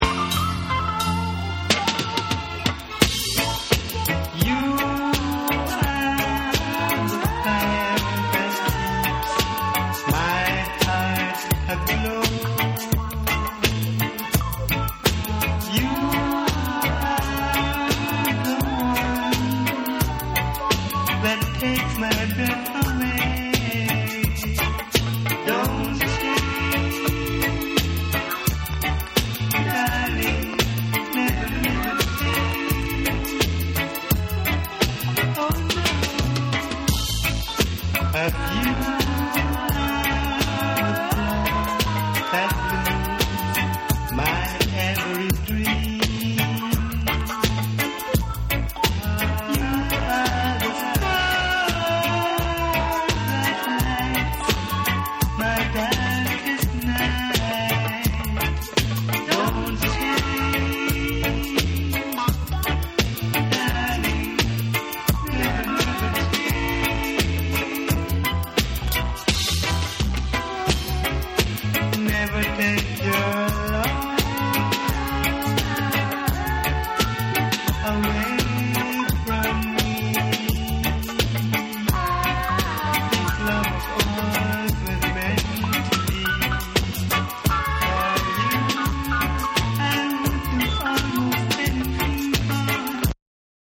※プレス段階で生じたセンターずれあり。※ジャマイカ盤特有のチリノイズあり。